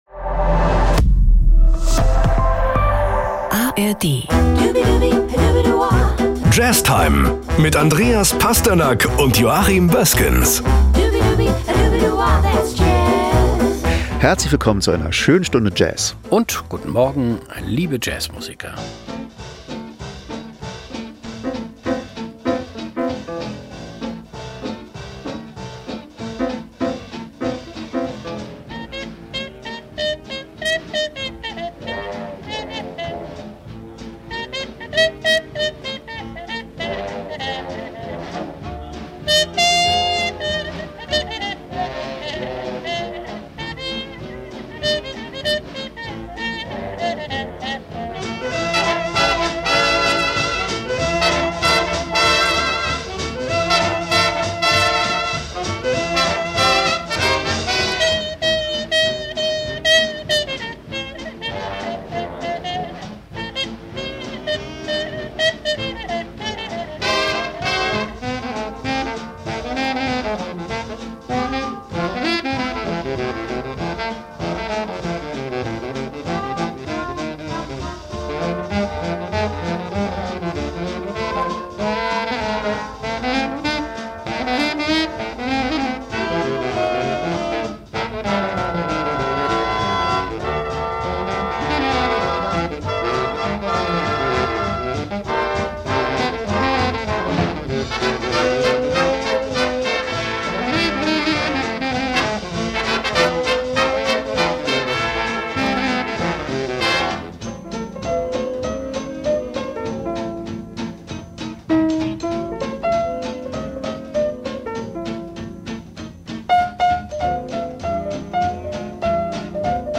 eine frühe Bebop Komposition